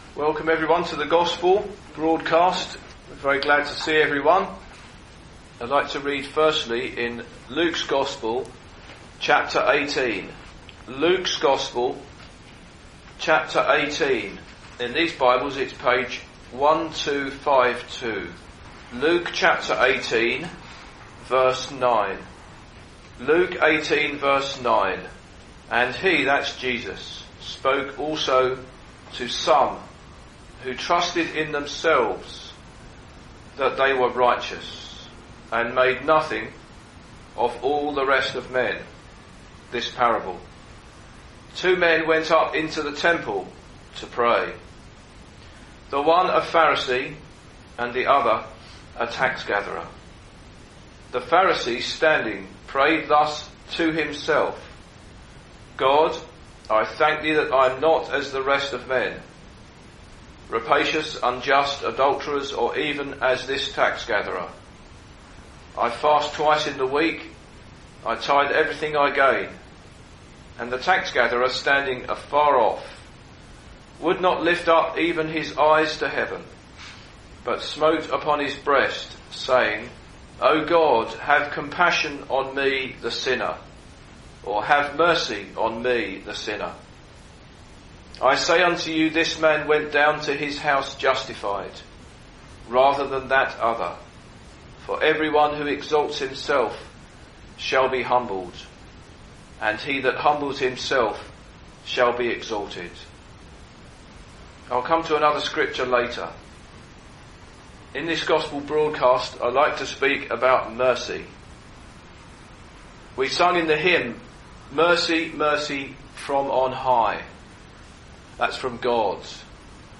In this Gospel preaching, you will hear a parable Jesus told about two men who went up to the temple to pray. One of these men received mercy from God as he cried out in repentance and faith.